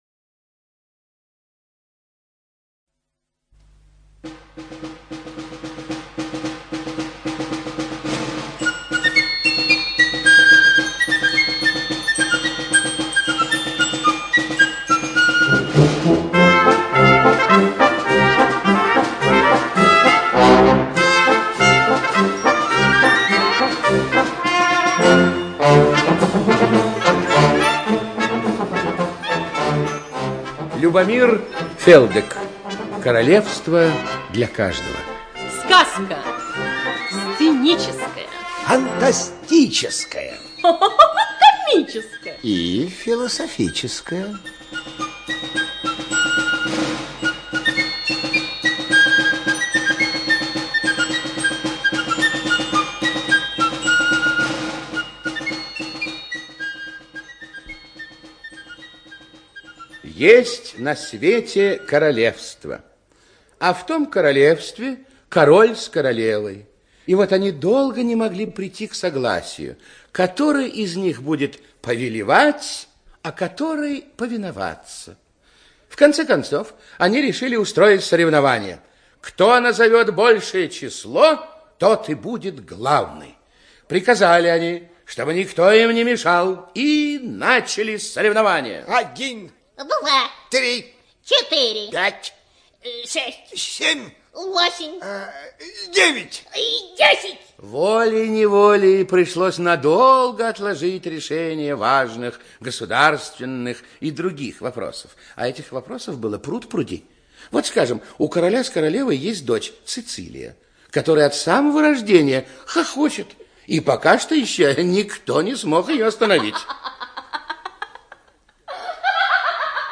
ЖанрСказки, Детский радиоспектакль